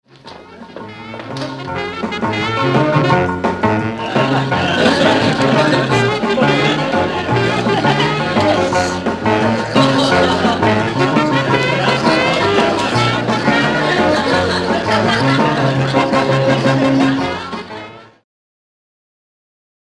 Кстати, шумы фильма из композиций я не стал вырезать.